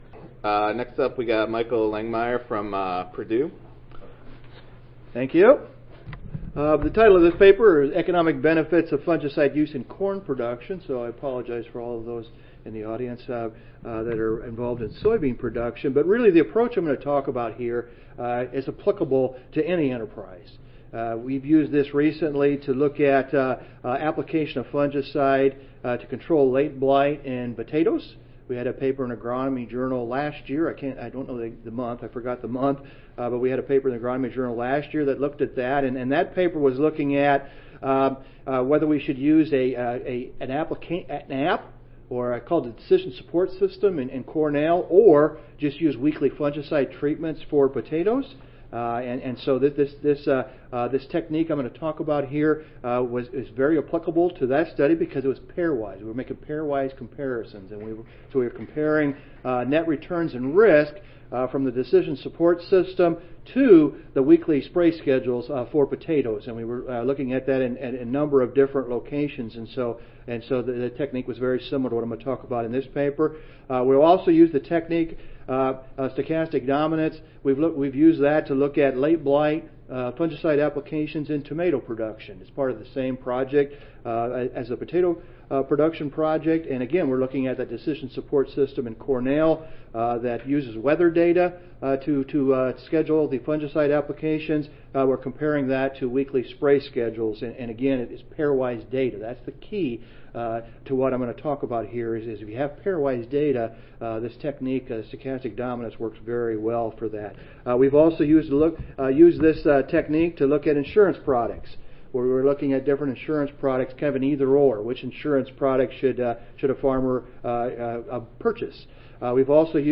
University of Kentucky Audio File Recorded Presentation